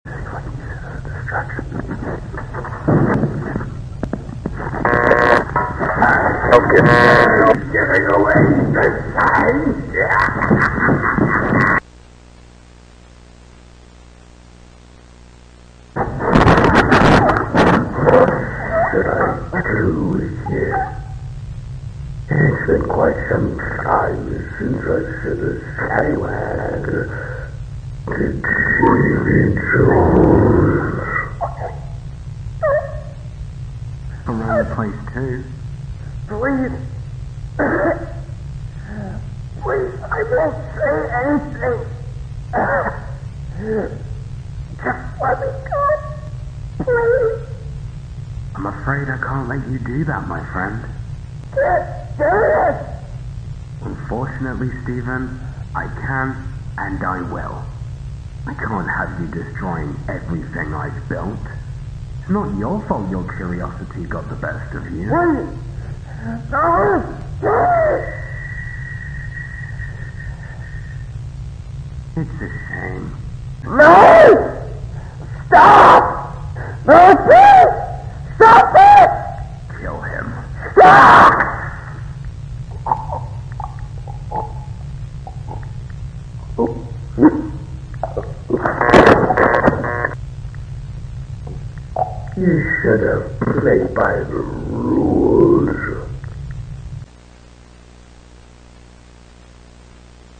⚠ LOUD SOUNDS + BLOOD sound effects free download